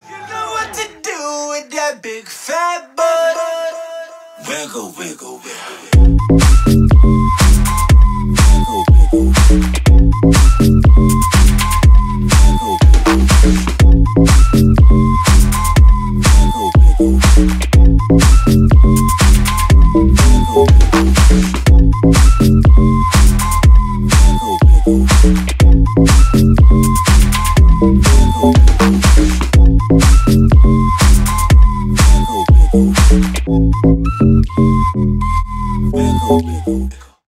• Качество: 320 kbps, Stereo
Ремикс
клубные